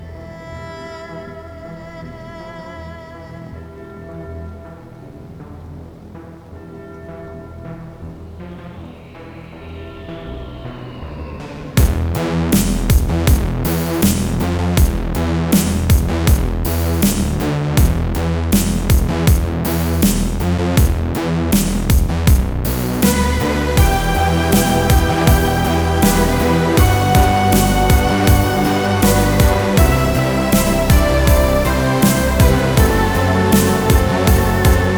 Жанр: Танцевальные / Поп / Электроника / Рок